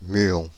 Ääntäminen
Synonyymit méson μ Ääntäminen France (Île-de-France): IPA: /my.ɔ̃/ Haettu sana löytyi näillä lähdekielillä: ranska Käännös 1. myon {n} Suku: m .